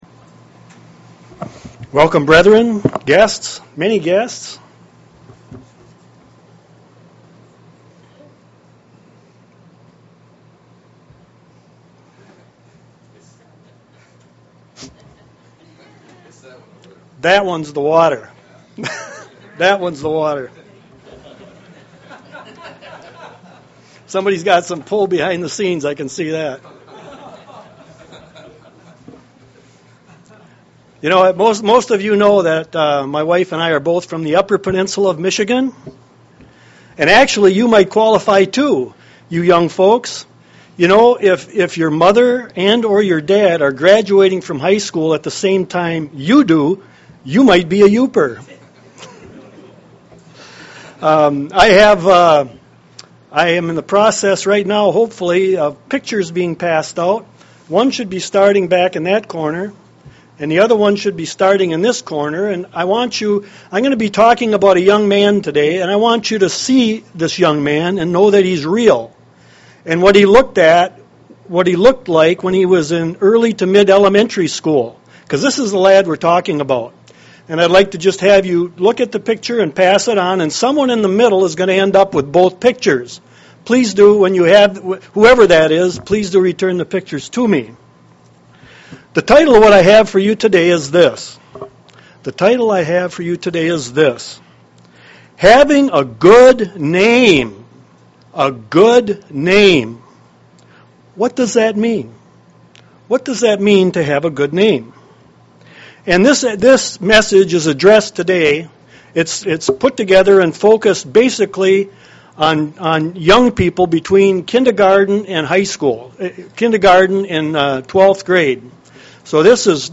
UCG Sermon Studying the bible?
Given in Lansing, MI